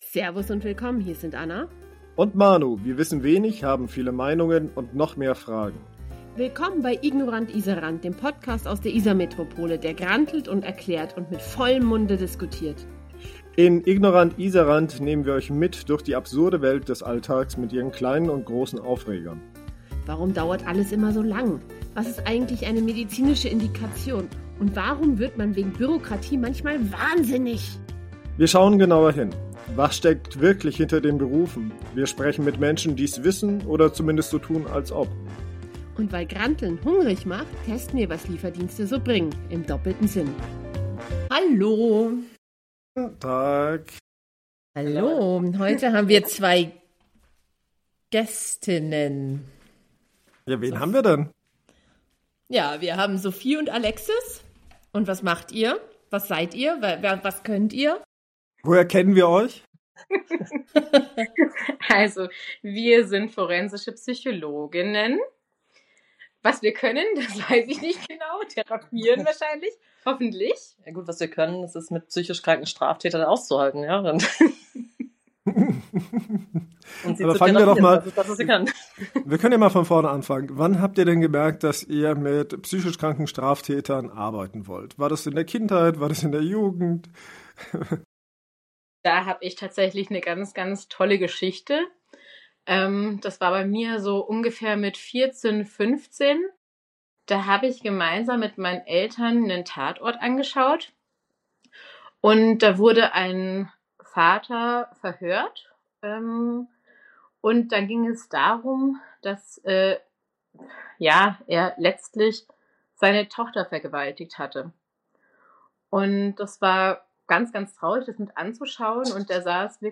In unserer letzten Folge vor der Winterpause haben wir etwas ganz Besonderes für euch: Wir sprechen mit zwei forensischen Psychologinnen, die täglich dort arbeiten, wo Psychologie, Strafrecht und Menschlichkeit aufeinandertreffen.